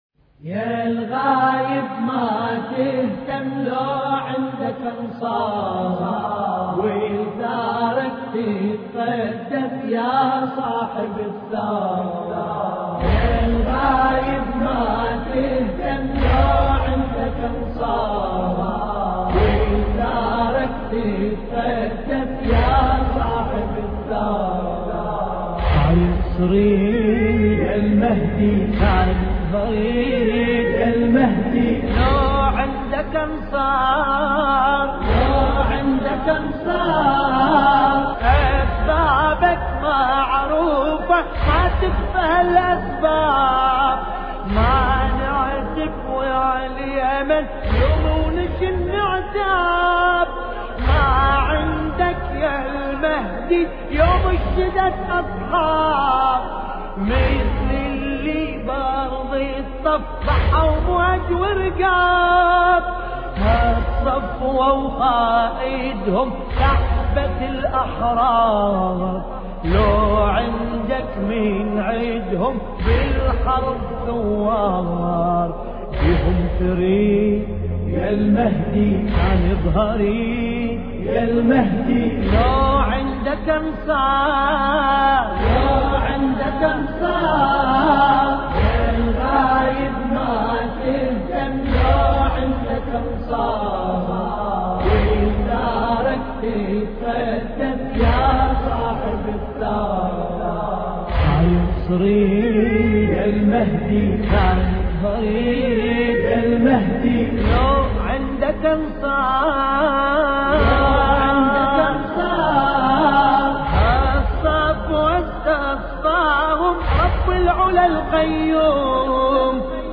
مراثي الامام المهدي (ع)